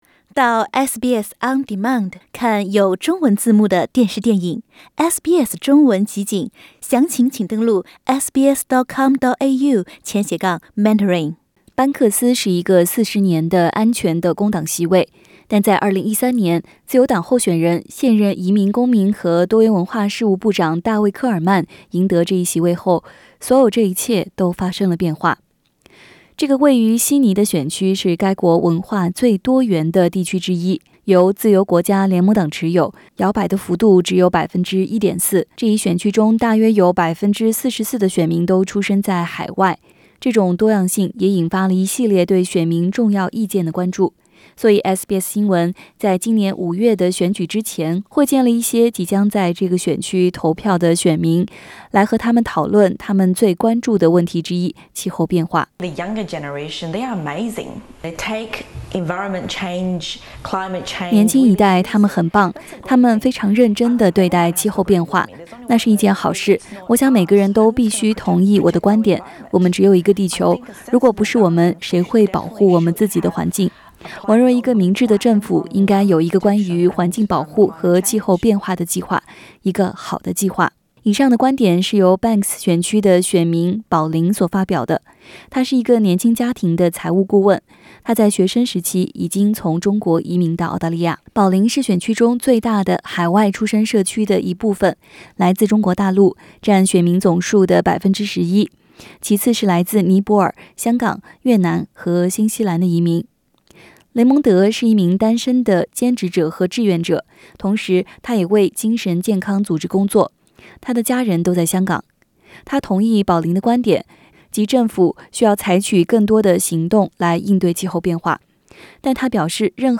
因此，SBS新闻对这个国家最摇摆的选区中的选民进行了采访，他们希望看到下一届政府解决这个问题。